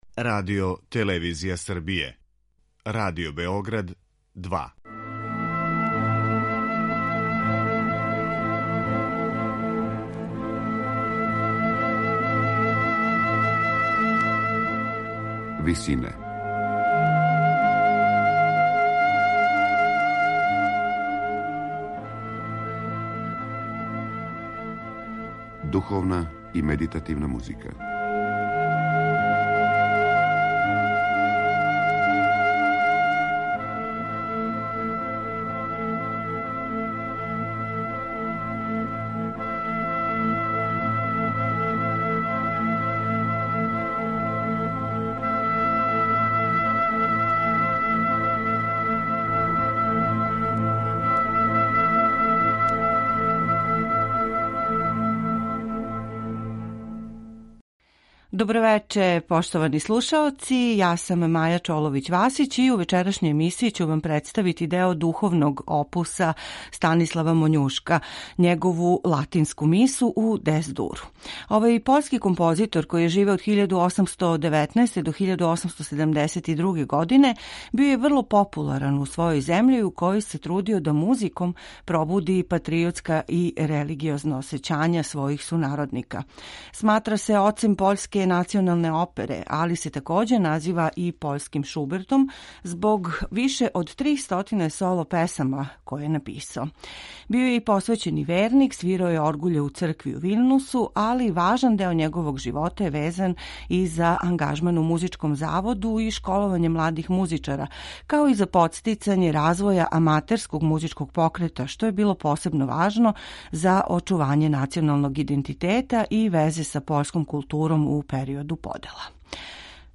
Био је и посвећени верник, свирао је оргуље у цркви у Виљнусу, те је за собом оставио и велики број духовних композиција, међу којима је и седам миса.
сопран
алт
тенор
бас
Латинска миса у Дес-дуру писана је за солисте, хор и оргуље и састоји се из осам ставова, уобичајеног мисног ординаријума. Уз примену контрапункта који није превише захтеван, непретенциозног и оптимистичног израза, први пут је изведена годину дана после настанка, 1871. године у Варшави.